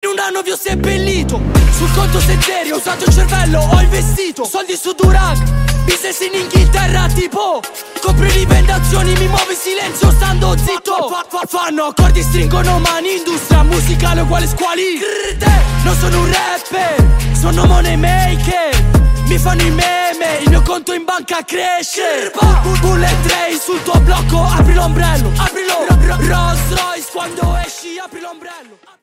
Categoria Rap/Hip Hop